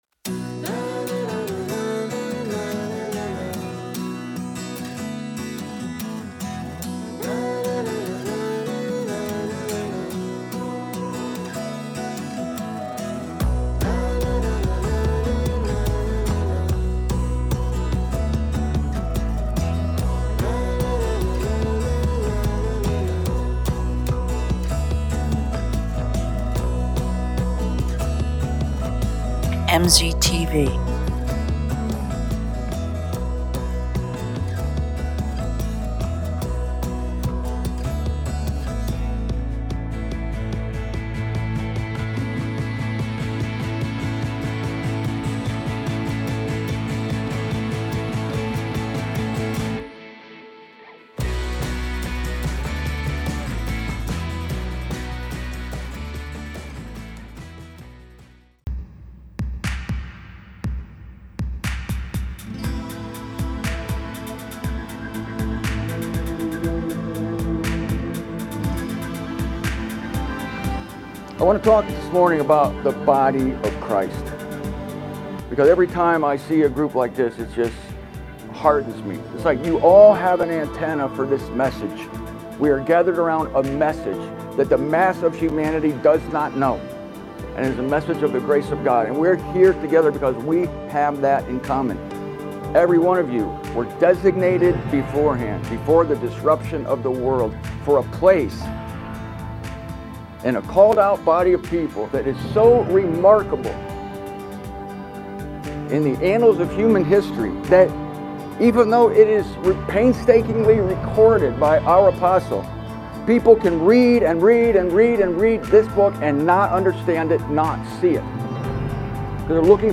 Recorded earlier this year at the Phoenix, AZ conference.